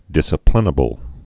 (dĭsə-plĭnə-bəl, dĭsə-plĭn-)